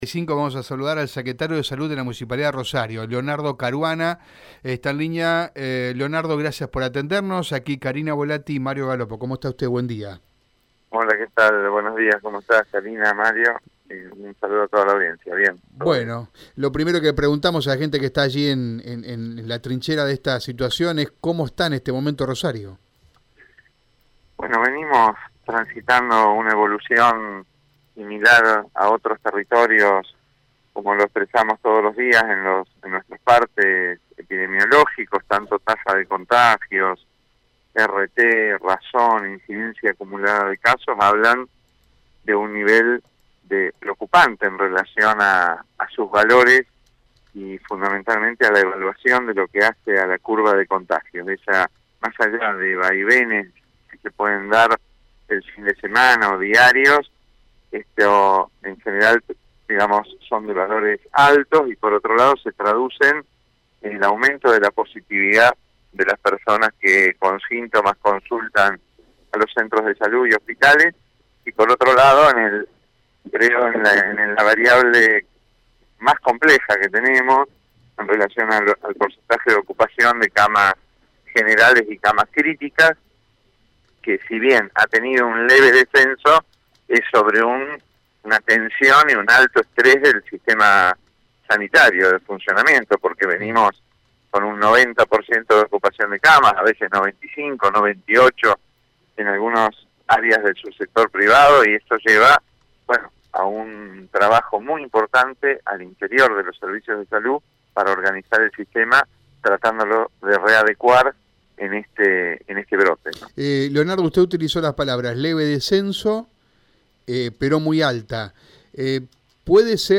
NOTA-Leonardo-Caruana-Secretario-de-Salud-de-Rosario.SITUACION-COVID.mp3